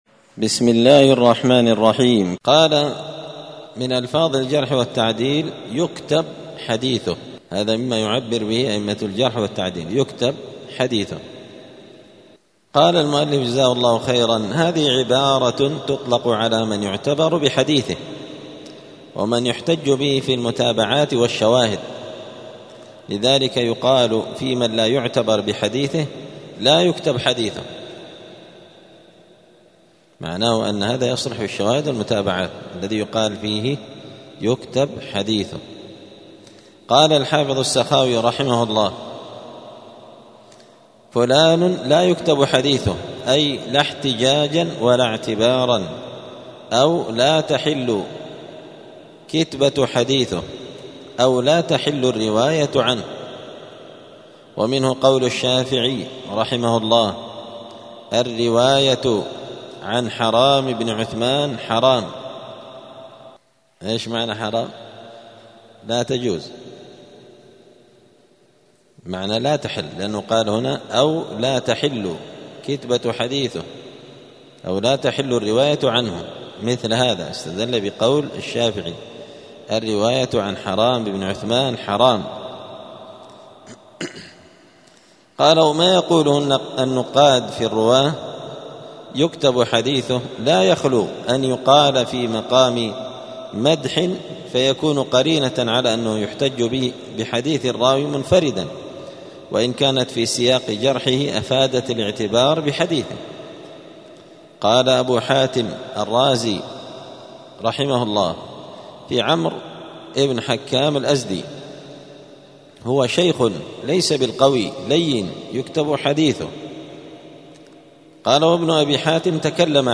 الجمعة 17 رجب 1446 هــــ | الدروس، المحرر في الجرح والتعديل، دروس الحديث وعلومه | شارك بتعليقك | 30 المشاهدات